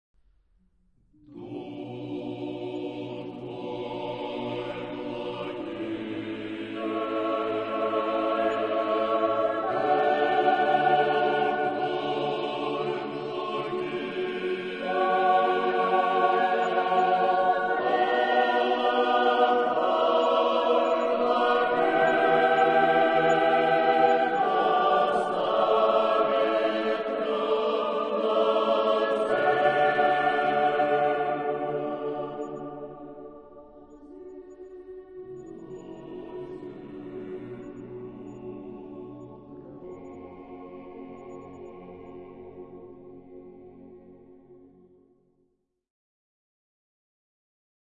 SSAATTBB (8 voices mixed) ; Choral score with piano for rehearsal only.
Orthodox liturgical hymn.
Sacred concerto.
Mood of the piece: solemn ; majestic ; prayerful Type of Choir: SSAATTBB (8 mixed voices )
Tonality: B minor